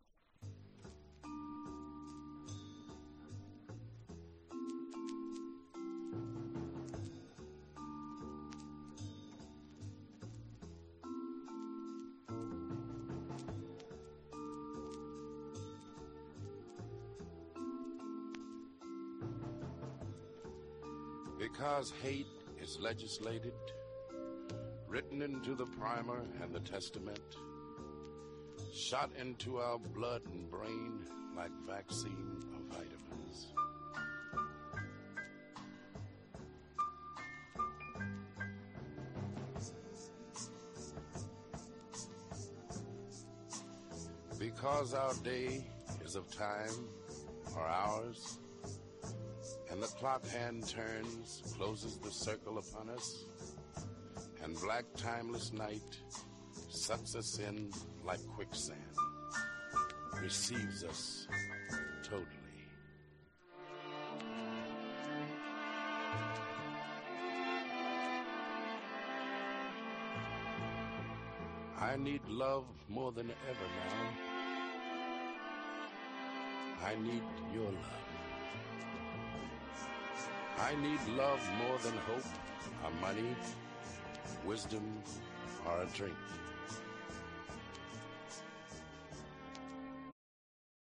ブラック・ムービーのサントラみたいで格好良いです。
# JAZZ FUNK / SOUL JAZZ